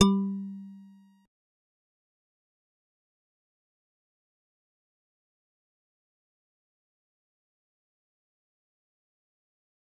G_Musicbox-G3-pp.wav